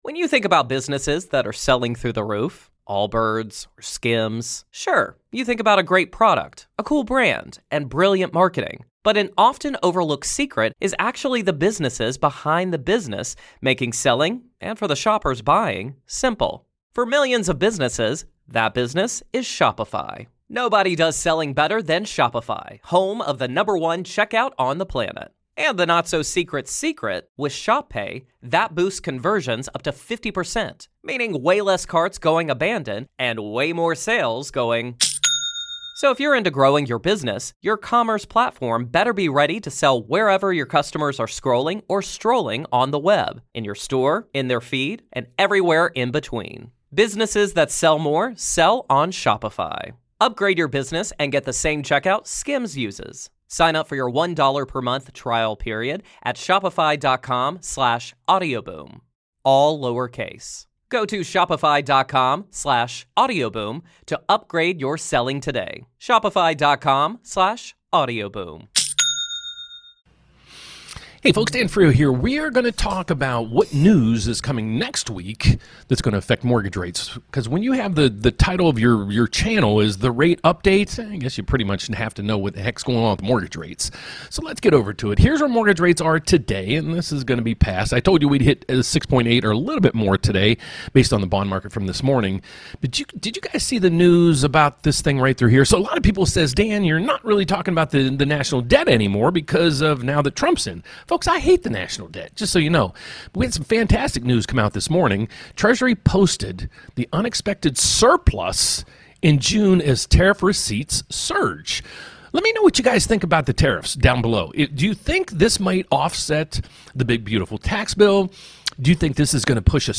LIVE: Crucial Inflation Reports Next Week — Is a Rate Cut Coming?